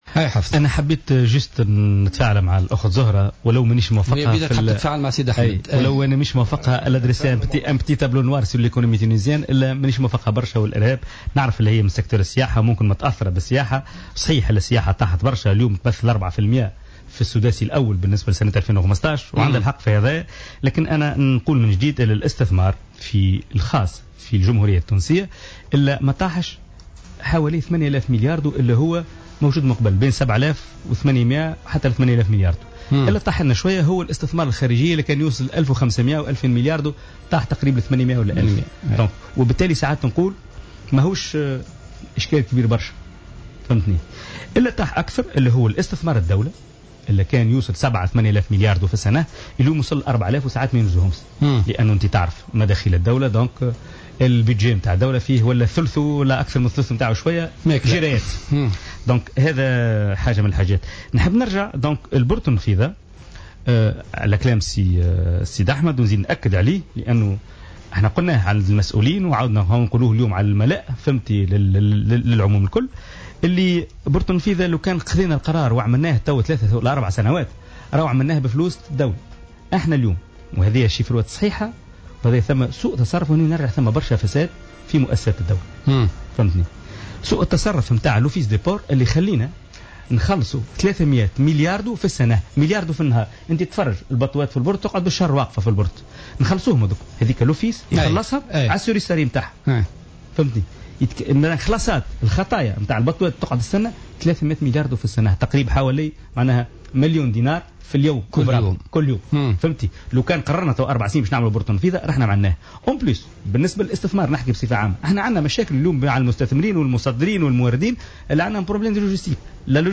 وأضاف النائب ورجل الأعمال في برنامج "بوليتيكا" على "الجوهرة أف أم" أنه بالإضافة إلى المشاريع الكبرى فإنه يسعى لإقناع السلطات بشأن مشروع خطة مرورية تمكّن من التخفيف من الضغط المروري بولاية سوسة، مشيرا إلى أن هناك مقترحا لاستخدام سكة حديدية بين سوسة والنفيضة.